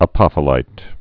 (ə-pŏfə-līt, ăpə-fĭlīt)